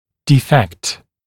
[‘diːfekt] [dɪ’fekt][‘ди:фэкт] [ди’фэкт]дефект, порок, недостаток, повреждение, нарушение